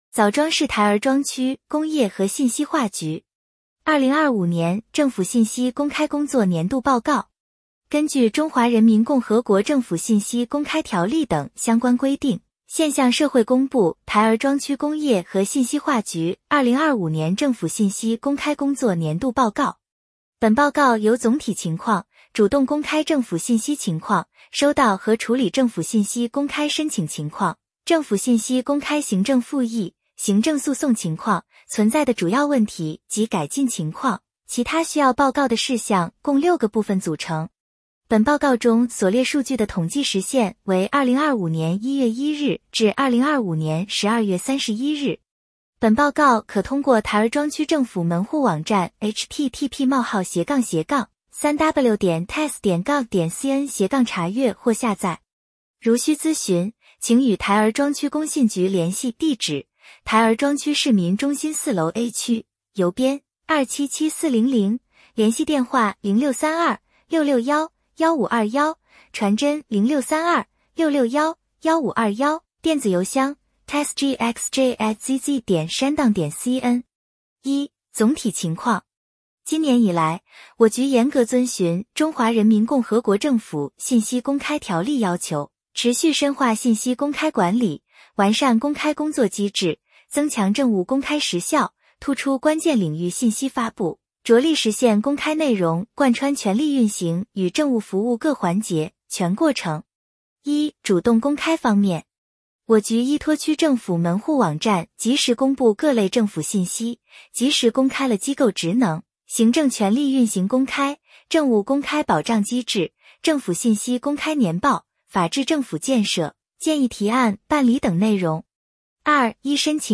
点击接收年报语音朗读 枣庄市台儿庄区工业和信息化局2025年政府信息公开工作年度报告 作者： 来自： 时间：2026-01-15 根据《中华人民共和国政府信息公开条例》等相关规定，现向社会公布台儿庄区工业和信息化局2025年政府信息公开工作年度报告。